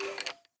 骷髅：咯咯声
空闲时随机播放这些音效
Minecraft_Skeleton_say3.mp3